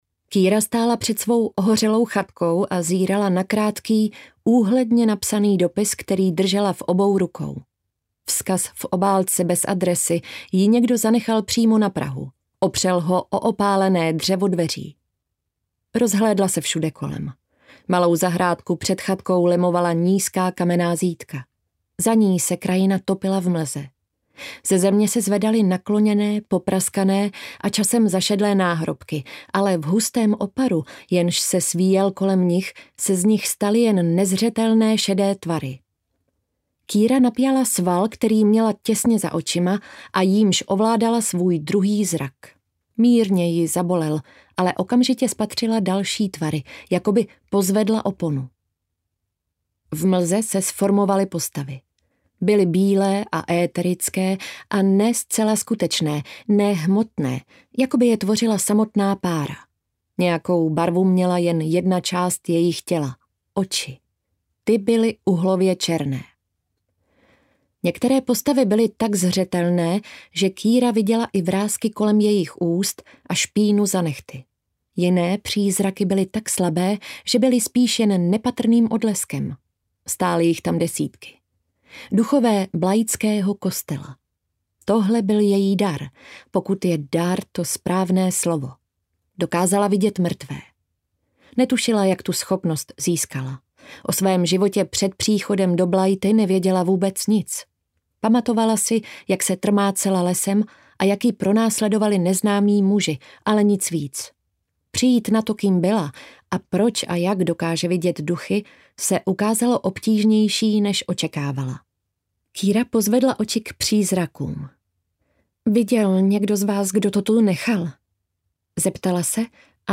Zrůdní mrtví audiokniha
Ukázka z knihy